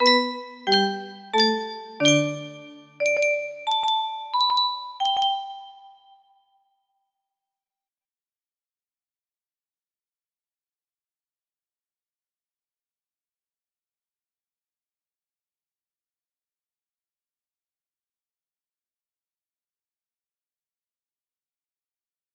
AFS Chime 1 music box melody